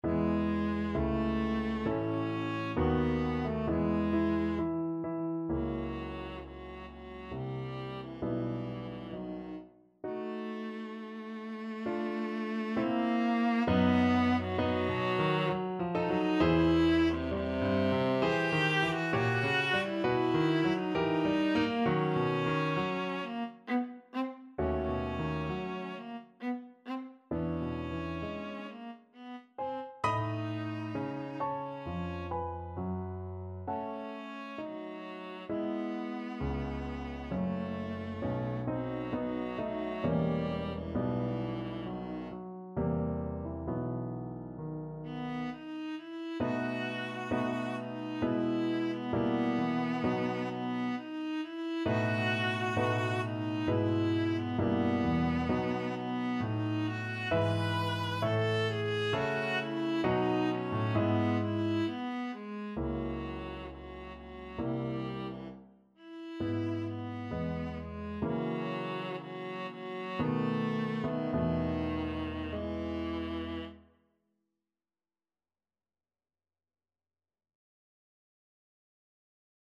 Viola
3/4 (View more 3/4 Music)
=66 Andante sostenuto
E major (Sounding Pitch) (View more E major Music for Viola )
Classical (View more Classical Viola Music)